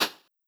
rotate.wav